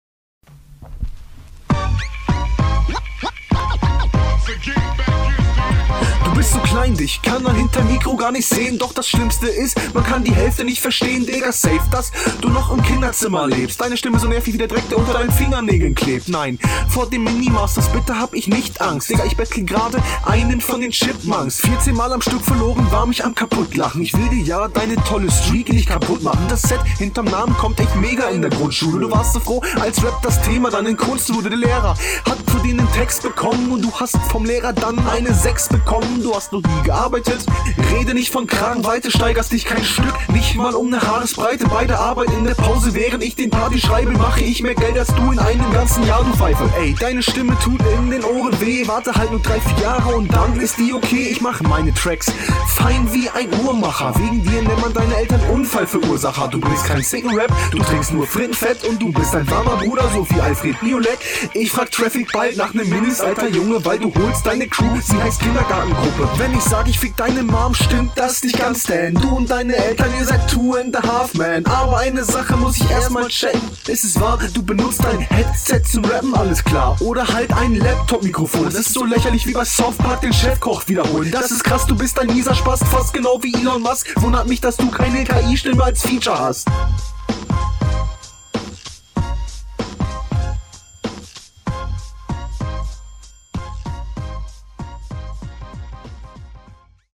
Die Dynamik im flow ist schon ganz nice. Die doubletime-Stellen sind leider nicht on point.
Doubletime ist dir noch zu hoch.